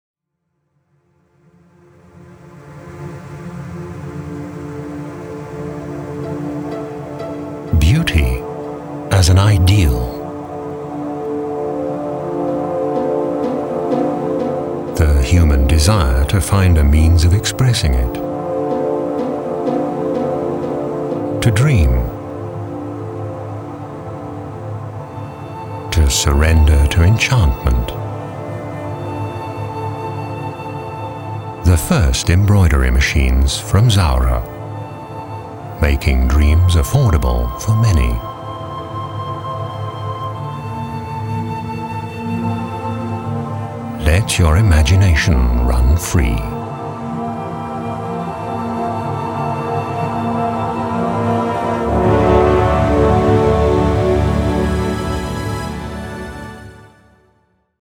Deutsch spricht er mit leichtem englischem Akzent.
Sprecher englisch uk.
Sprechprobe: Sonstiges (Muttersprache):
voice over artist english (uk)